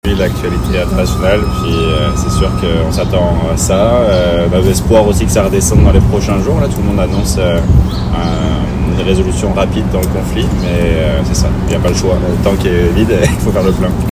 Un automobiliste sondé directement à la pompe exprime son soulagement de déménager bientôt à un endroit qui réduira ses déplacements sur la route.